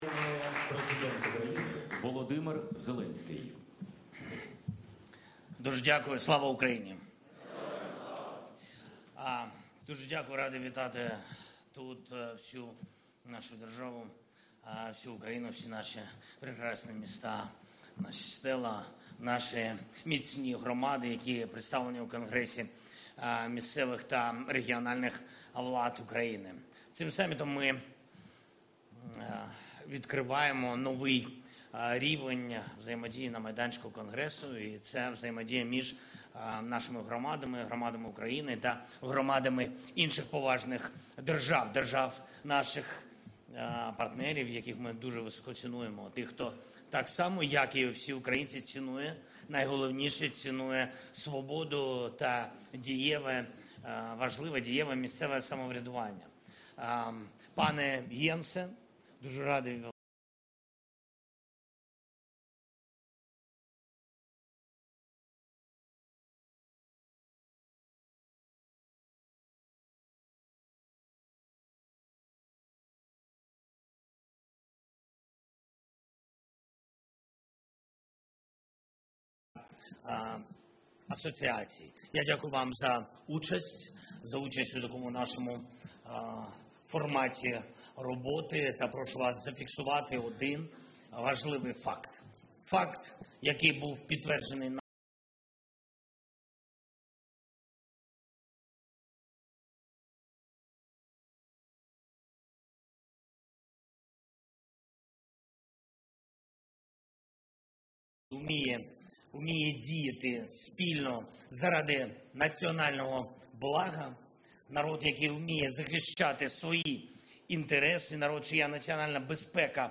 Speech
by NATO Secretary General Jens Stoltenberg at the International Summit of Cities and Regions in Kyiv, Ukraine